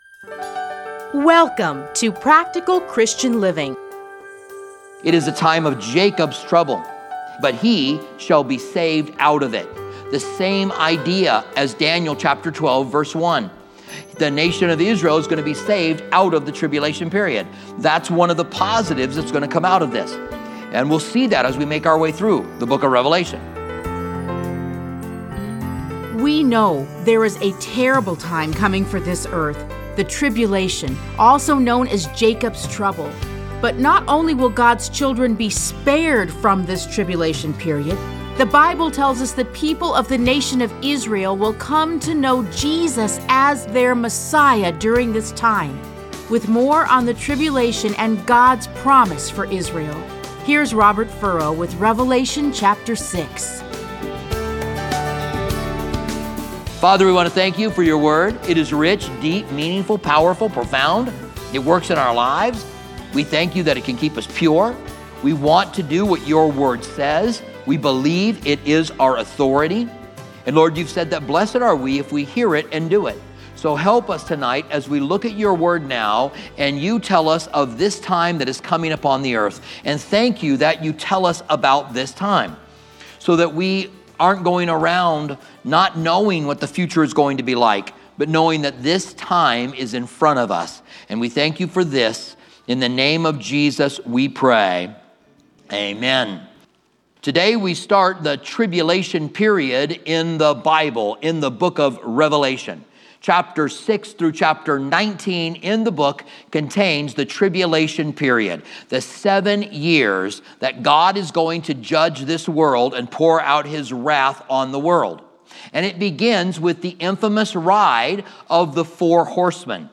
Listen to a teaching from Revelation 6:1-8.